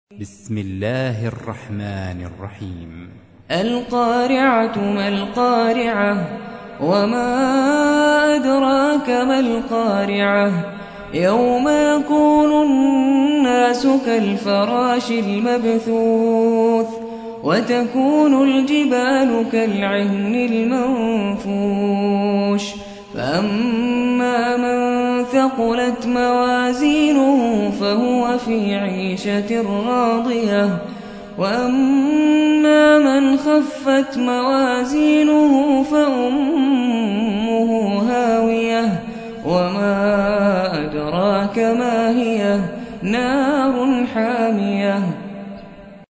101. سورة القارعة / القارئ